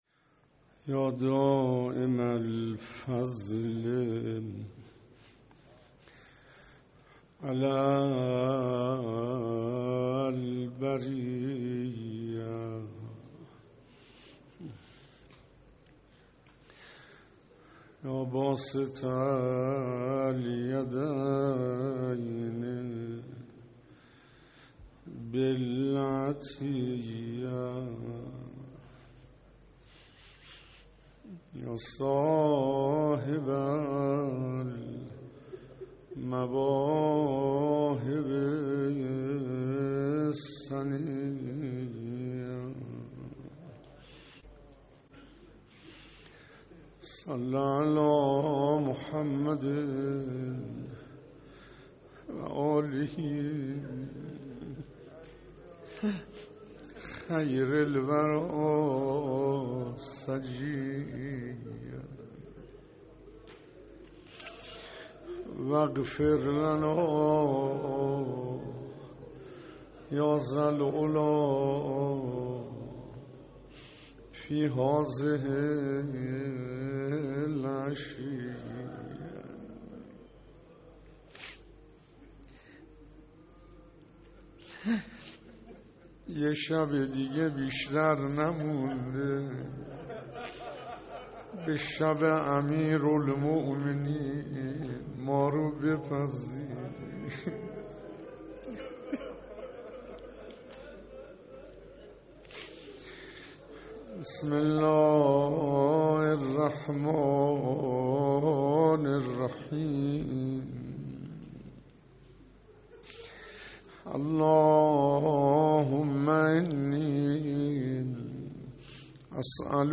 خطرات پیش روی انسان - جلسه شانزدهم پنج شنبه * دعای کمیل* (9-2-1400) - رمضان 1442 - حسینیه همدانی‌ها - 27.34 MB -